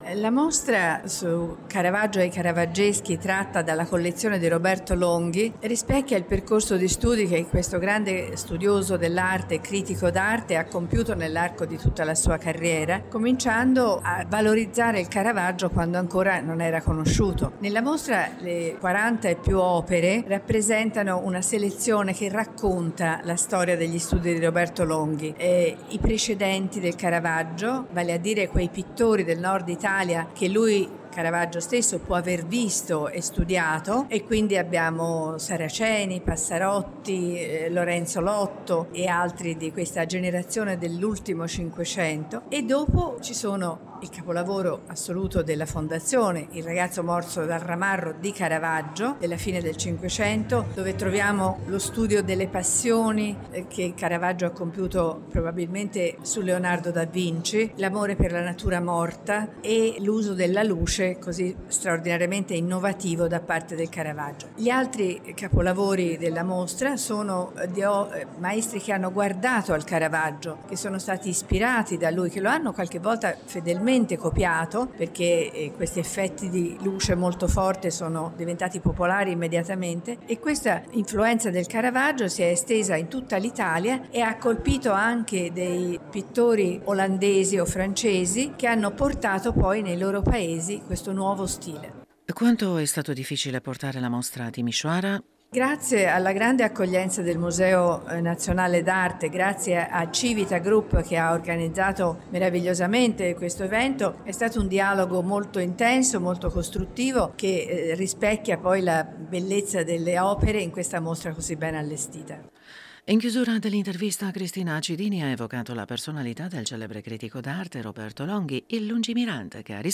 in un’intervista